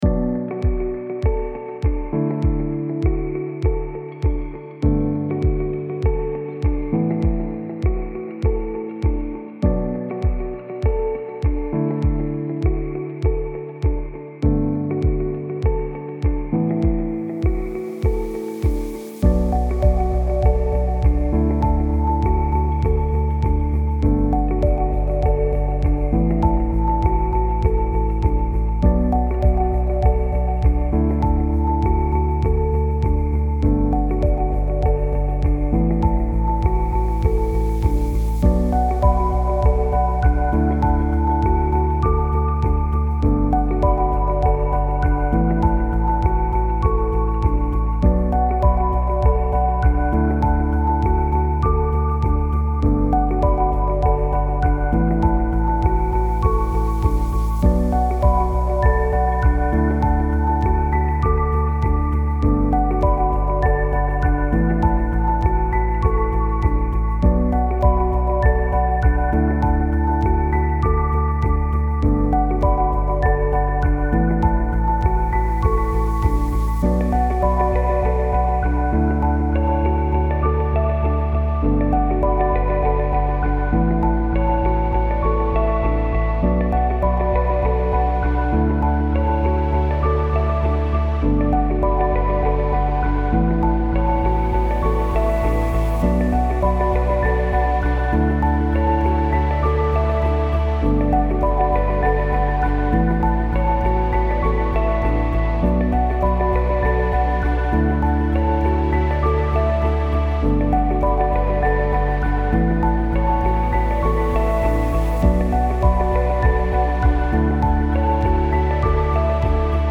beautiful_style_ambient_11328.mp3